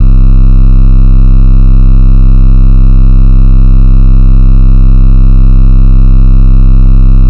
Gamer World Bass 1.wav